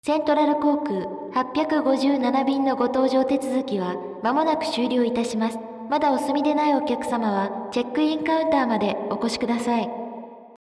/ M｜他分類 / L50 ｜ボイス
b4 空港 搭乗アナウンス